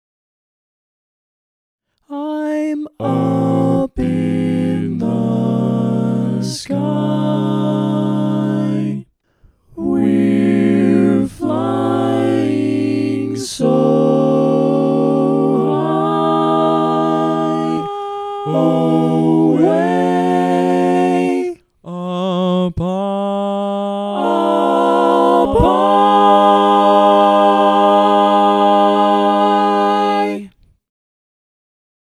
Key written in: A♭ Major
How many parts: 4
Type: Barbershop
All Parts mix:
Learning tracks sung by